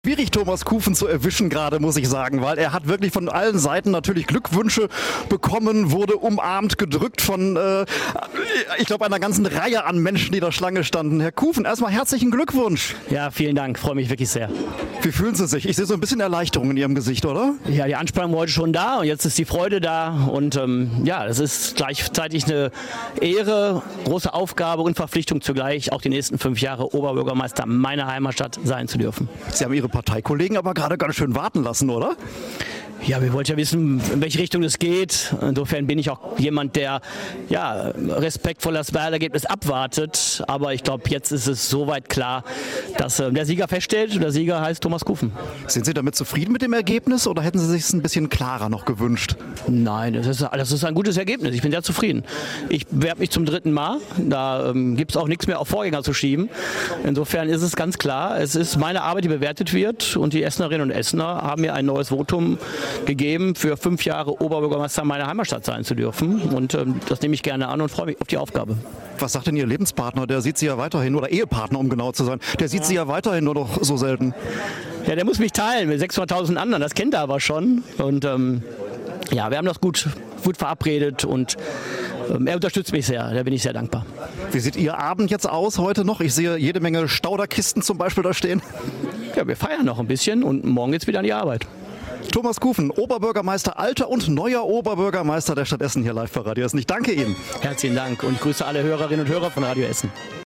"Die Anspannung war heute schon da, jetzt ist die Freude da und ja es ist gleichzeitig eine Ehre, große Aufgabe und Verpflichtung zu gleich, auch die nächsten fünf Jahre Oberbürgermeister meiner Heimatstadt sein zu dürfen.", so Thomas Kufen im Radio-Essen-Interview.